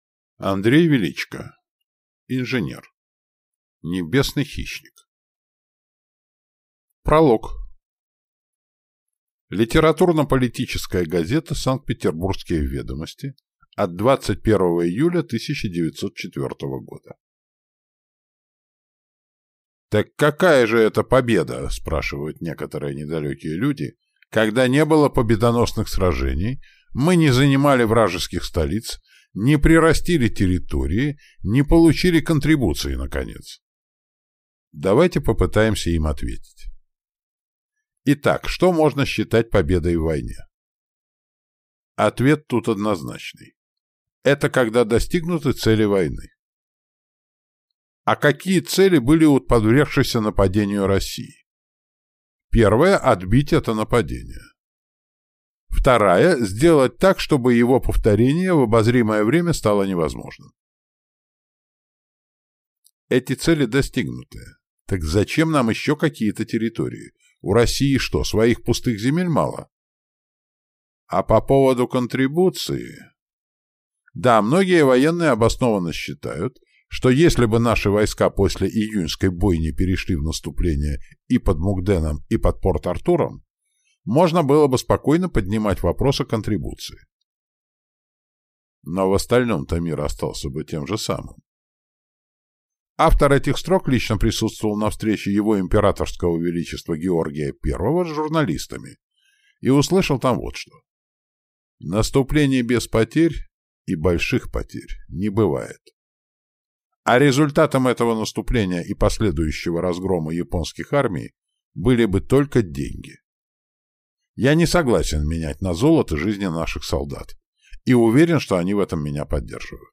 Аудиокнига Инженер. Небесный хищник | Библиотека аудиокниг